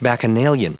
Prononciation du mot bacchanalian en anglais (fichier audio)
Prononciation du mot : bacchanalian